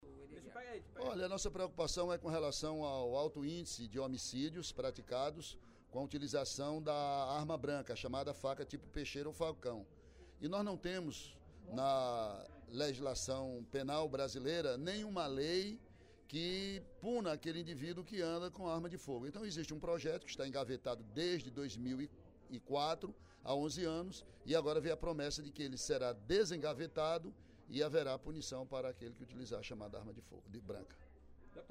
Durante o primeiro expediente da sessão plenária desta quinta-feira (28/05), o deputado Ely Aguiar (PSDC) criticou a falta de legislação para disciplinar o uso de armas brancas no País.